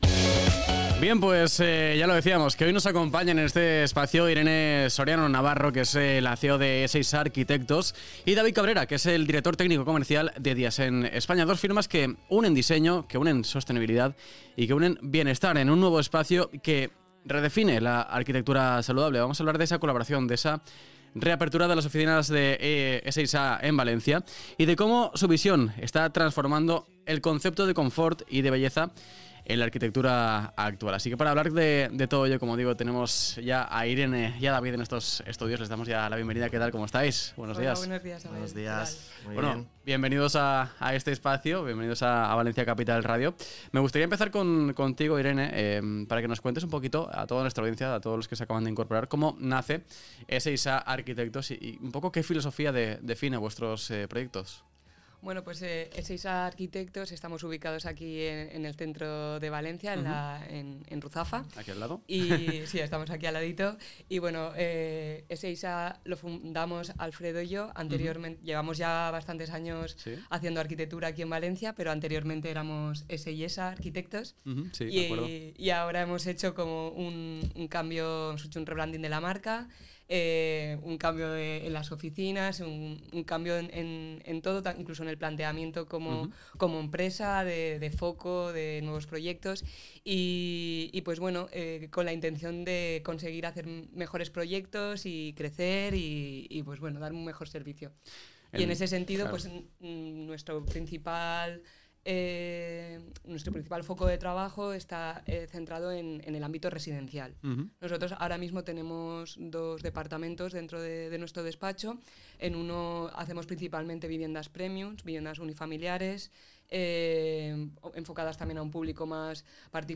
Entrevista a E6A Arquitectos y Diasen España – AJEV